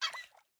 Minecraft Version Minecraft Version snapshot Latest Release | Latest Snapshot snapshot / assets / minecraft / sounds / mob / axolotl / idle_air3.ogg Compare With Compare With Latest Release | Latest Snapshot
idle_air3.ogg